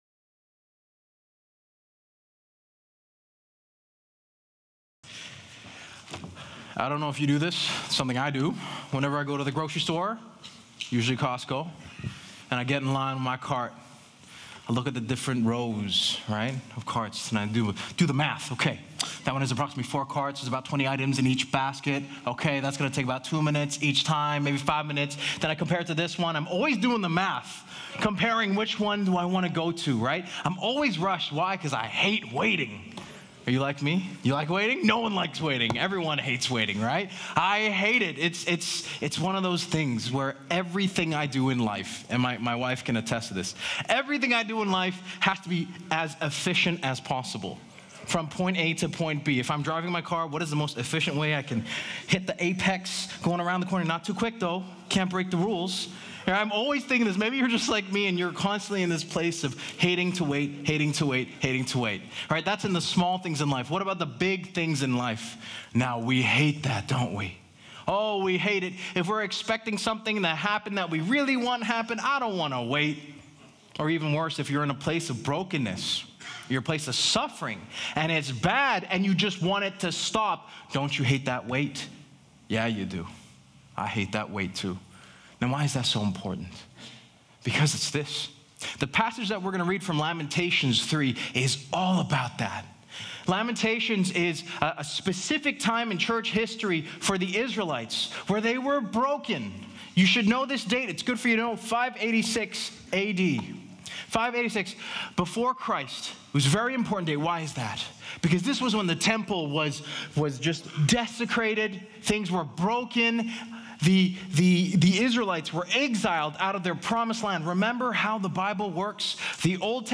Last Sunday’s Message